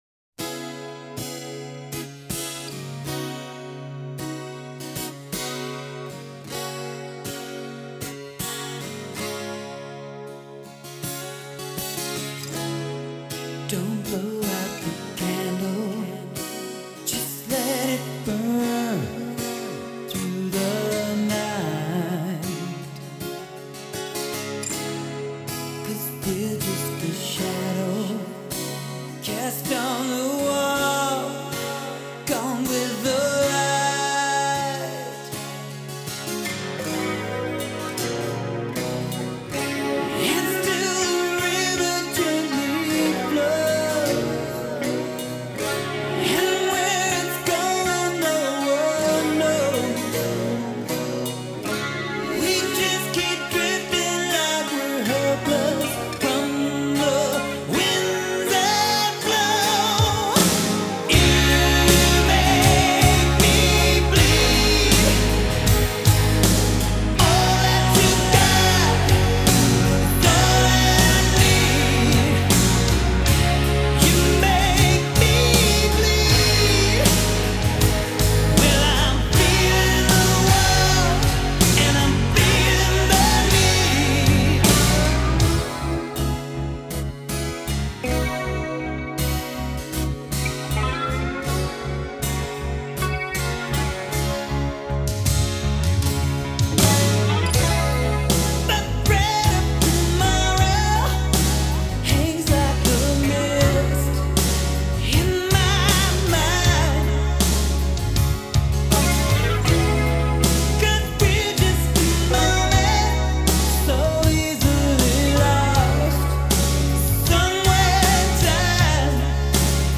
Назад в Rock